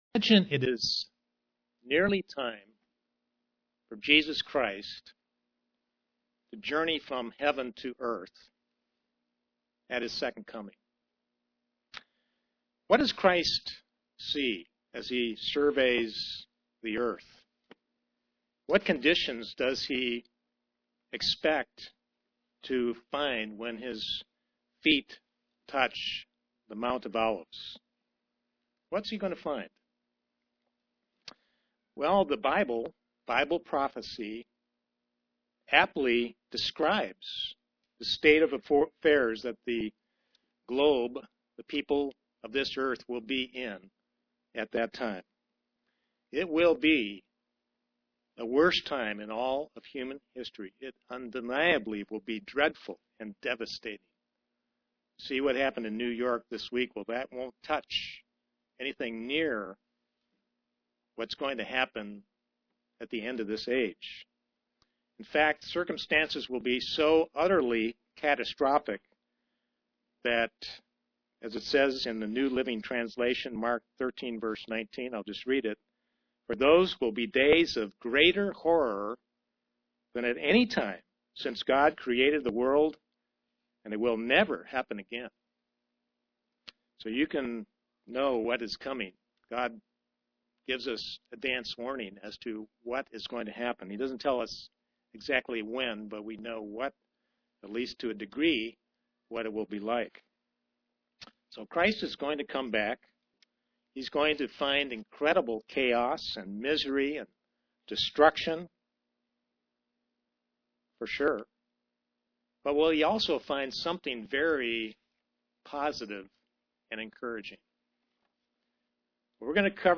Print The answer is yes if we will stay close to God UCG Sermon Studying the bible?